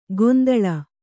♪ gondaḷa